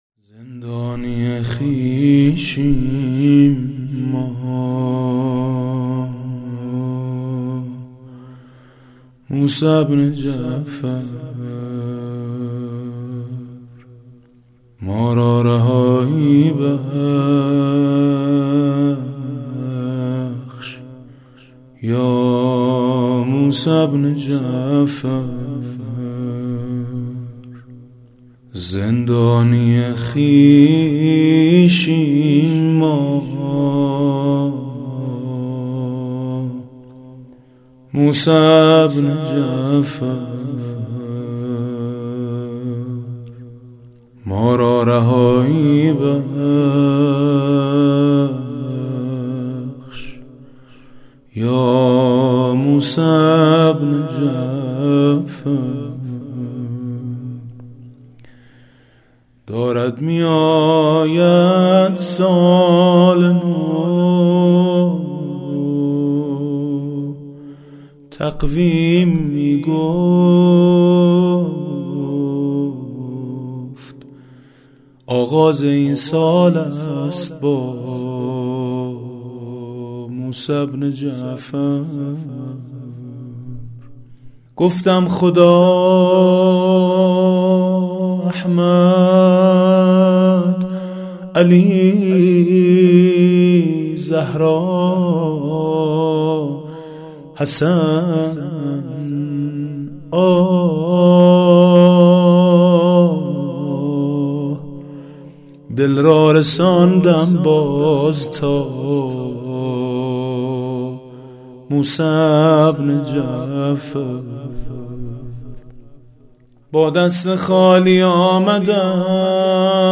روضه و مرثیه ها